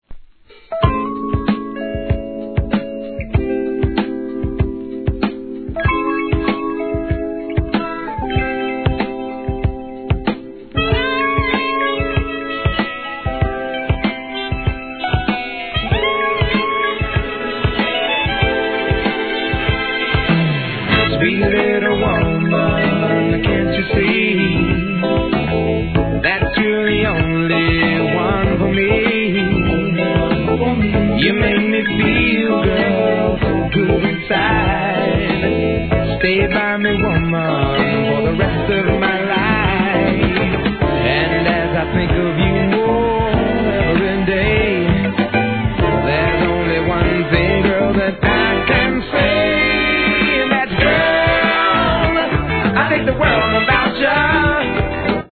¥ 1,980 税込 関連カテゴリ SOUL/FUNK/etc...